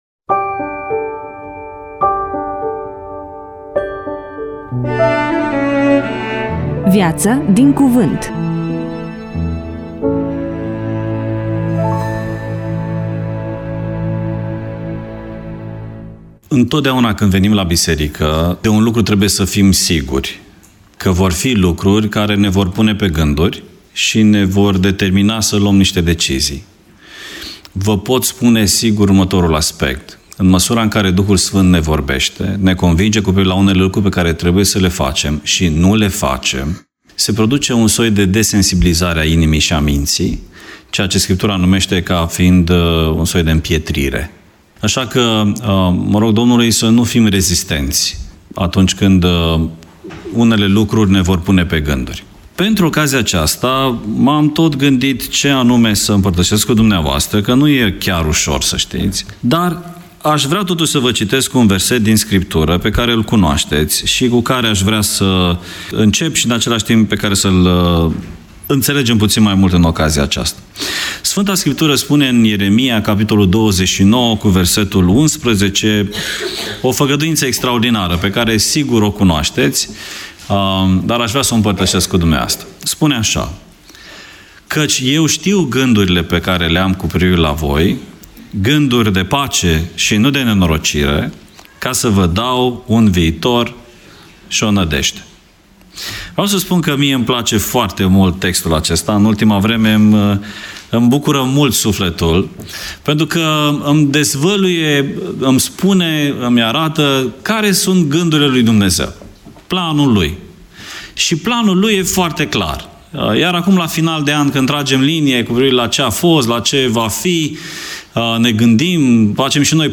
EMISIUNEA: Predică DATA INREGISTRARII: 07.02.2026 VIZUALIZARI: 13